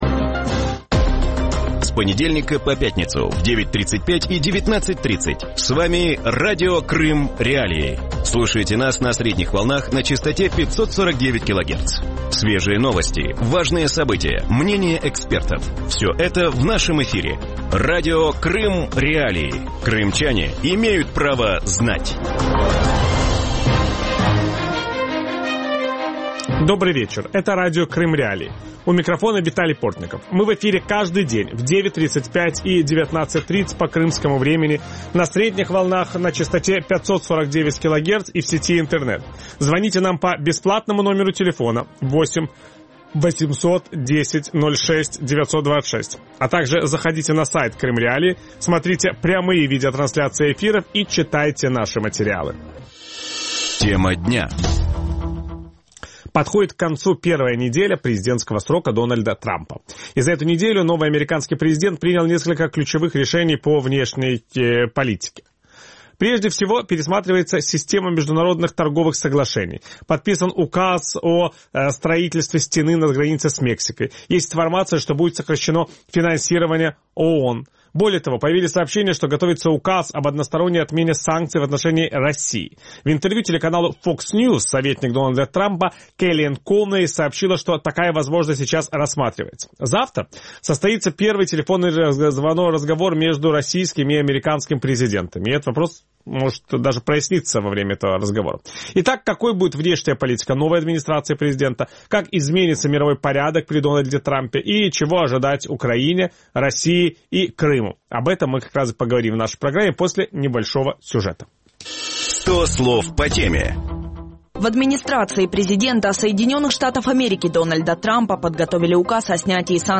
В вечернем эфире Радио Крым.Реалии говорят о будущем внешней политики США во время президентского срока Дональда Трампа. Станет ли изоляционизм ключевой повесткой американской политики, сможет ли Трамп договориться с Россией и как это повлияет на мировой порядок?
Ведущий программы – Виталий Портников.